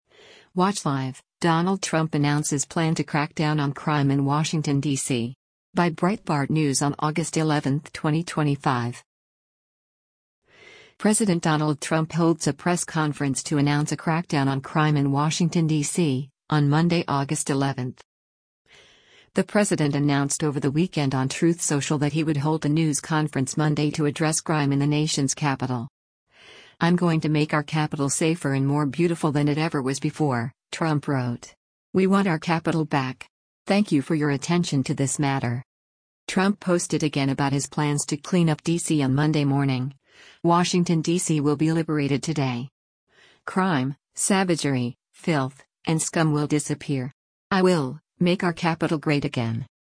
President Donald Trump holds a press conference to announce a crackdown on crime in Washington, DC, on Monday, August 11.